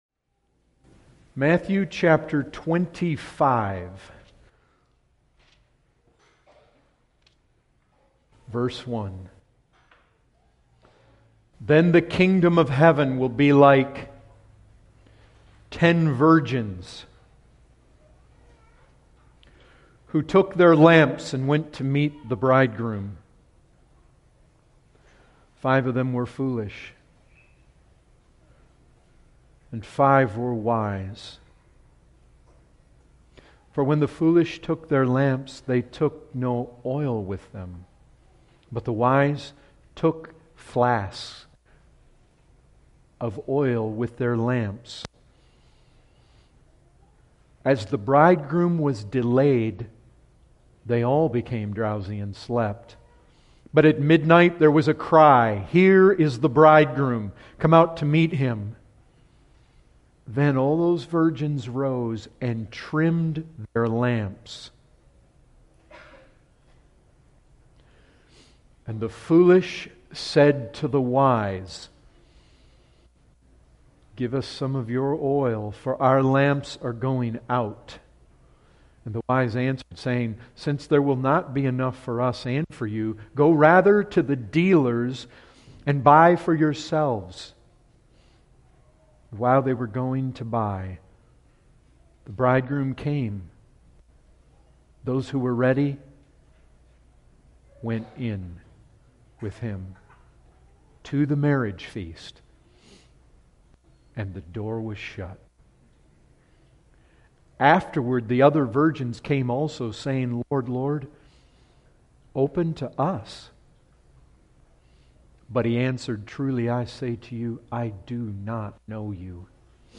This sermon explores the parable of the ten virgins found in Matthew 25.